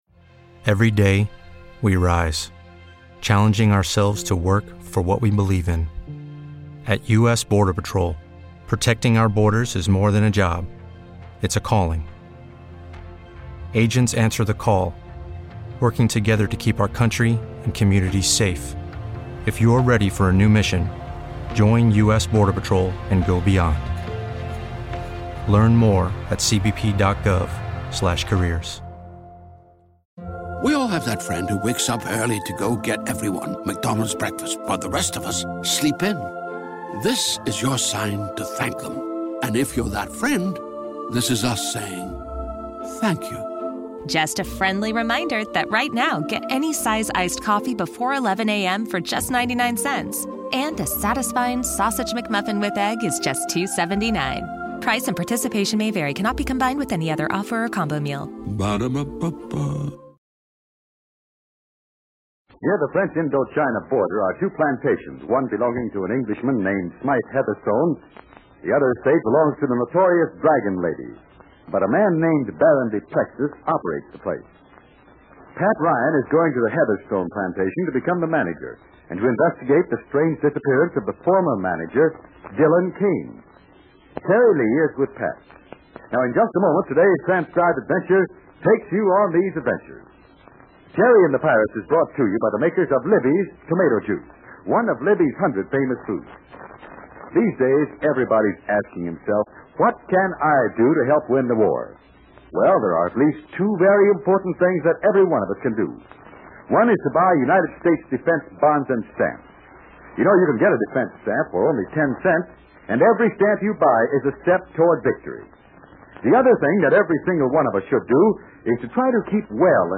Terry and the Pirates was an American radio serial adapted from the comic strip of the same name created in 1934 by Milton Caniff. With storylines of action, high adventure and foreign intrigue, the popular radio series enthralled listeners from 1937 through 1948.